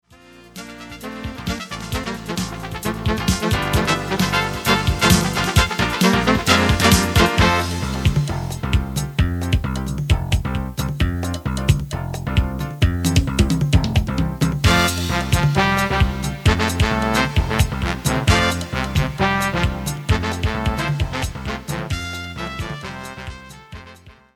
Writing of modern style Jazz.